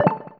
notification-pop-in.wav